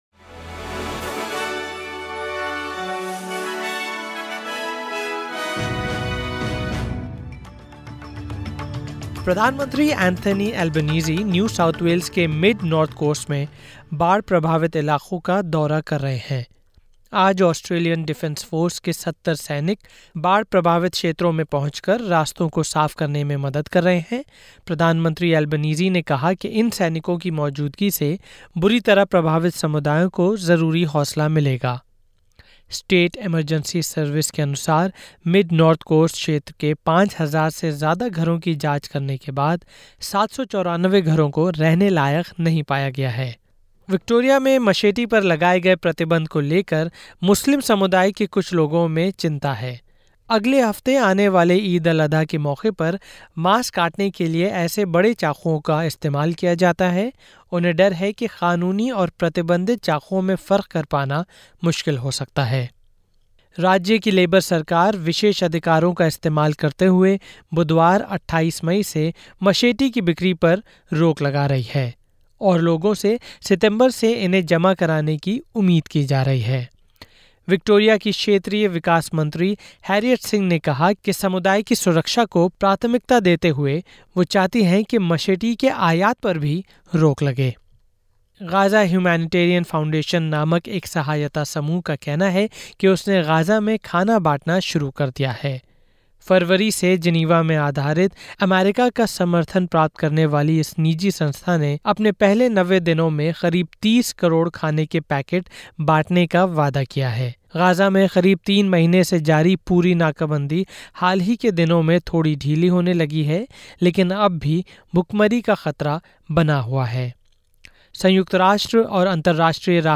Listen to the top News of 27/05/2025 from Australia in Hindi.